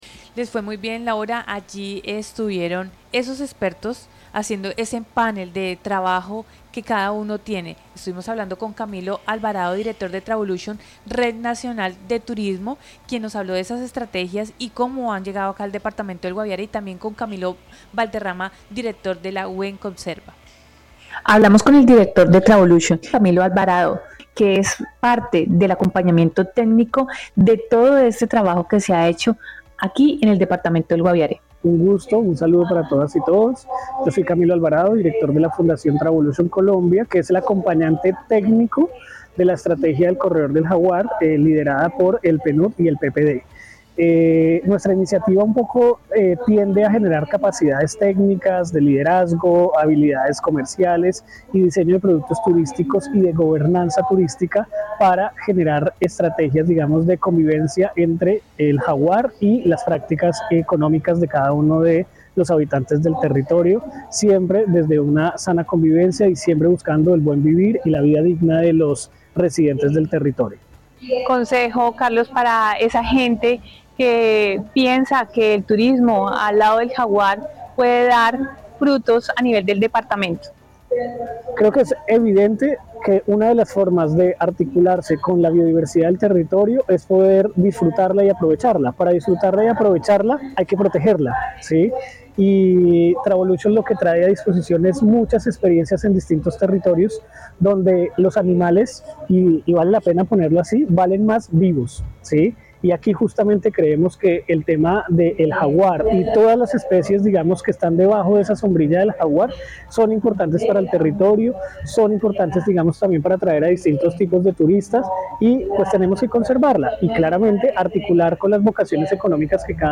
Expertos en biodiversidad, turismo comunitario y conservación se reunieron en un ciclo de conversatorios realizado en el Centro Cultural.